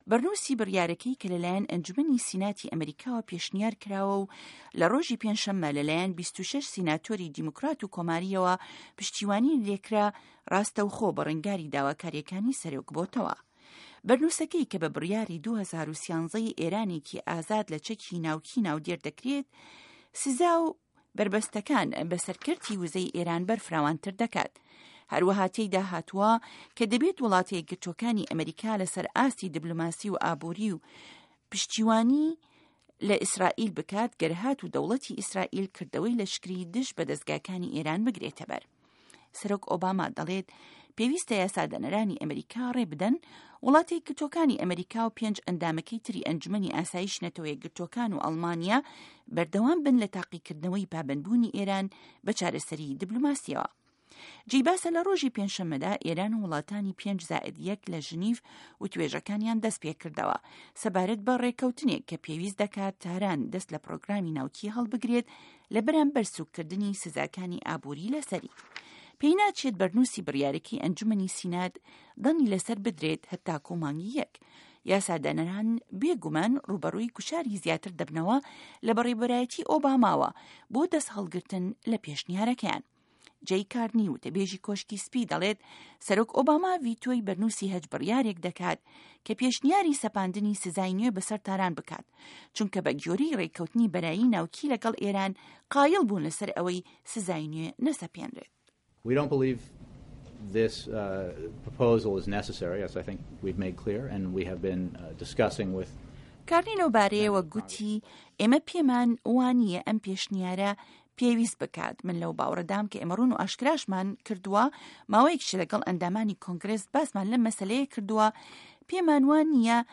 ڕاپـۆرتی ئێران